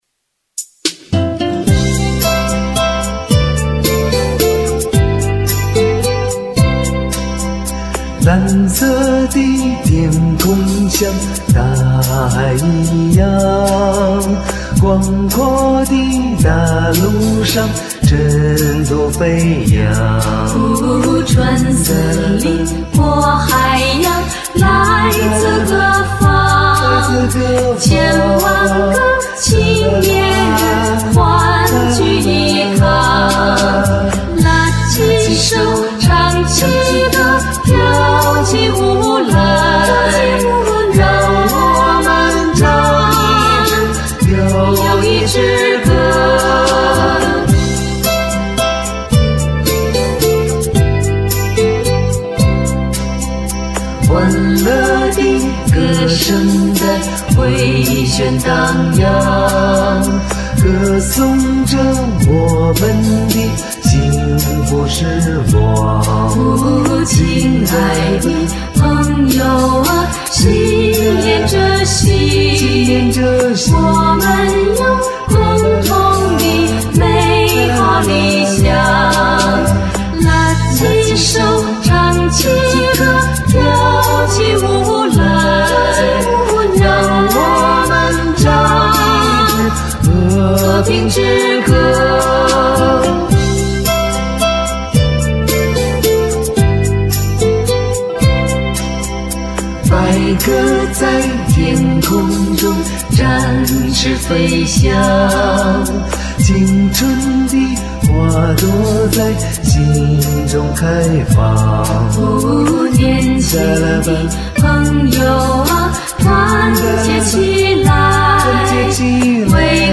歌曲是我从网上一个32kbps 用录音软件录成128 的，不值得下载。
只是因为喜欢三拍子的歌，就自己学会了。
只是喜欢华尔兹热情洋溢的欢乐旋律。